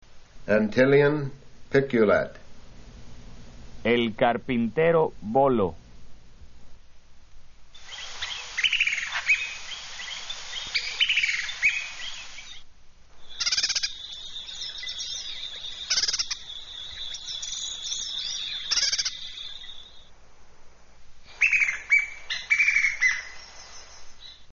Bird Sounds from Hispaniola
Antillean-Piculet
Antillean-Piculet.mp3